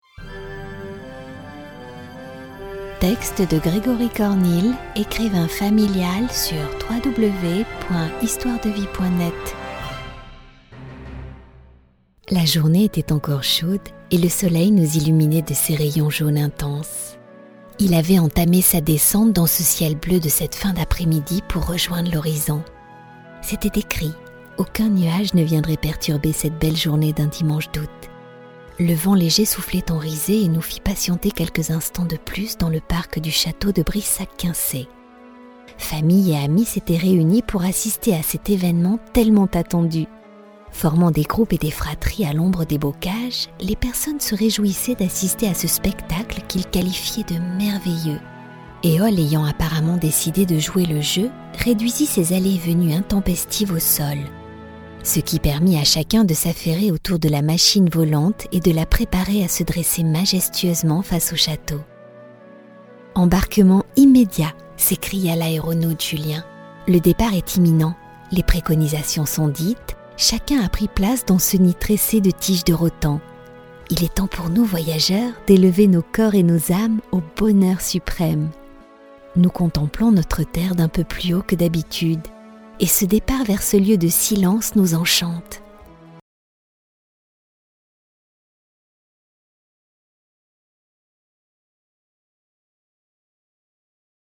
Comédienne voix-off professionnelle tout type de voix et de projet.
Sprechprobe: Sonstiges (Muttersprache):
Professional voiceover actress all types of voice and project.